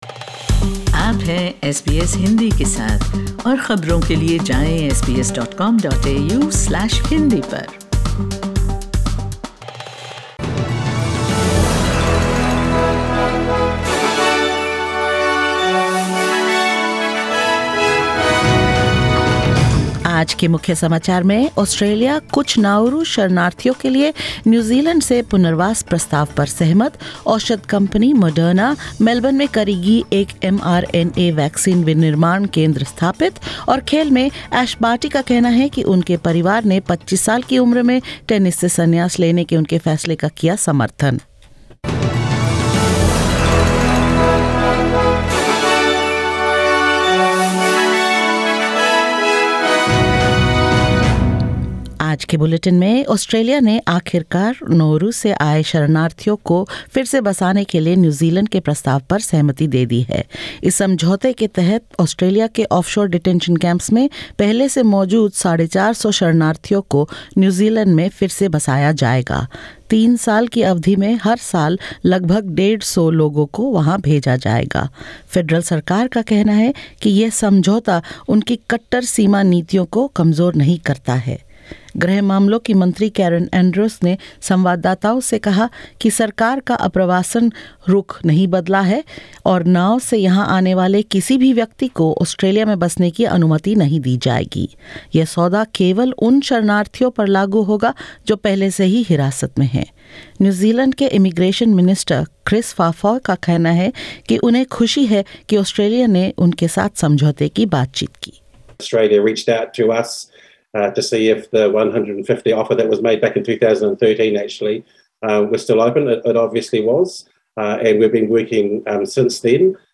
In this latest SBS Hindi bulletin: Australia agrees to take up New Zealand's refugee resettlement offer for 450 Nauru refugees; Moderna to set up first mRNA vaccine manufacturing hub in Melbourne; Ash Barty’s family supports her decision to retire from tennis at the age of 25 and more.